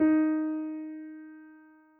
piano_051.wav